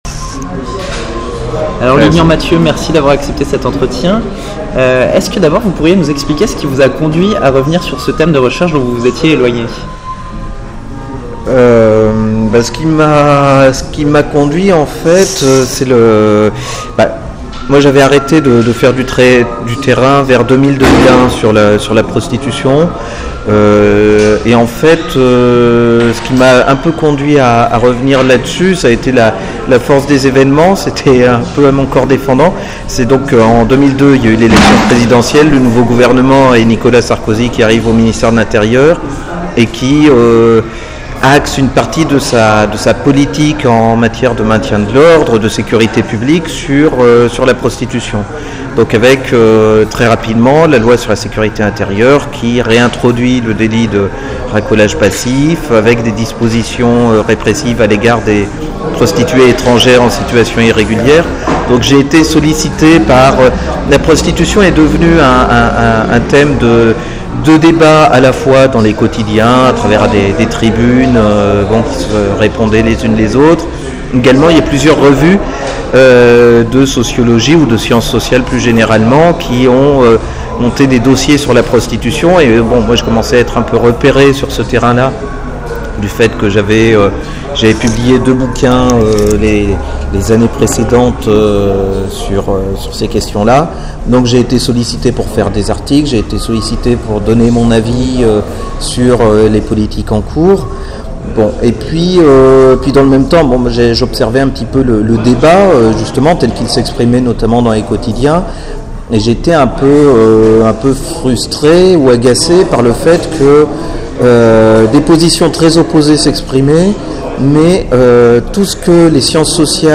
L'entretien audio (au format mp3)